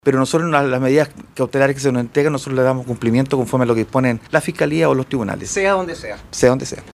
Radio Bío Bío en Temuco también le consultó por el caso al General Director de Carabineros, Ricardo Yáñez, quien si bien admitió desconocer los detalles de la orden entregada a la institución, aseguró que todas las decisiones judiciales serán cumplidas.